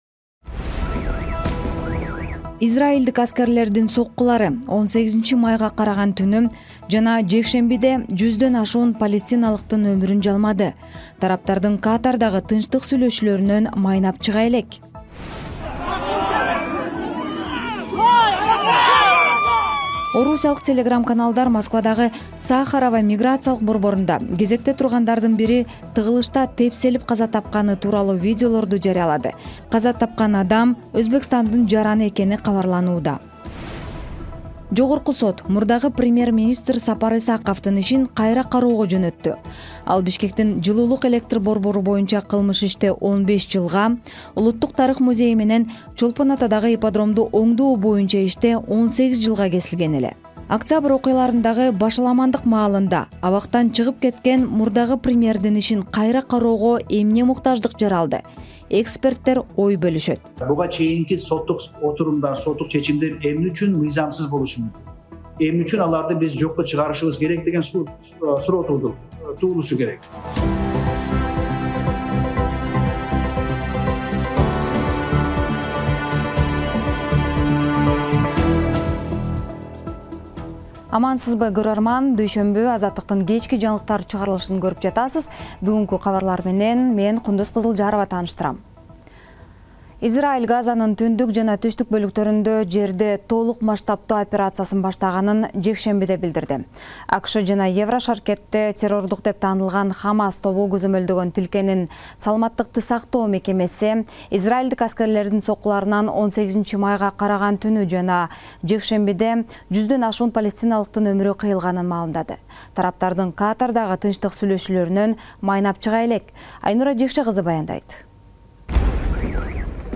Жаңылыктар | 19.05.2025 | “Сахароводо” тыгында мигрант тепселип каза тапты